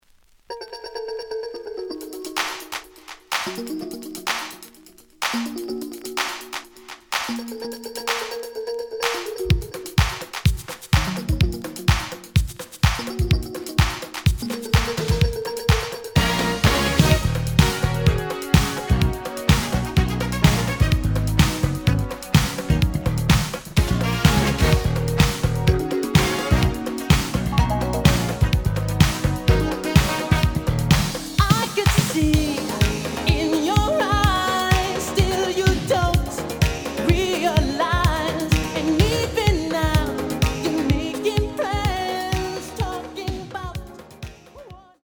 The audio sample is recorded from the actual item.
●Genre: Disco
Slight edge warp. But doesn't affect playing. Plays good.